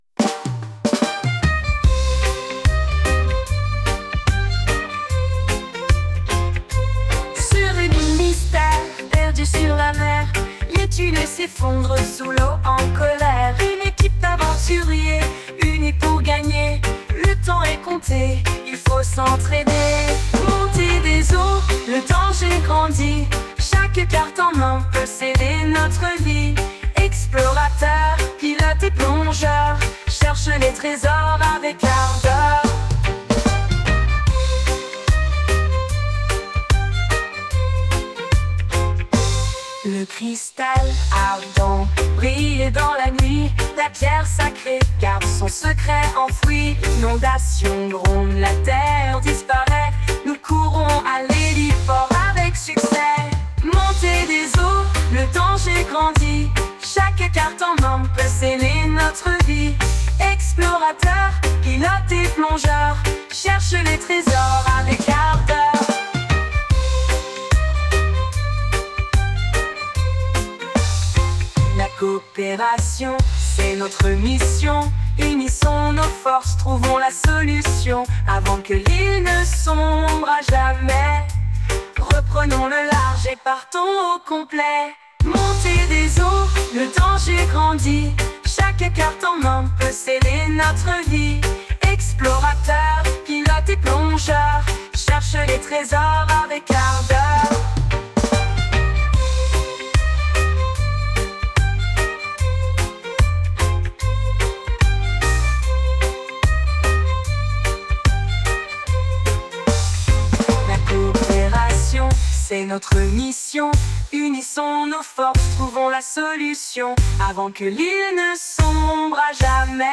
Revenons sur des jeux plus "strat�gique" , avec un jeu, une �le, un reggae : musique jeu 102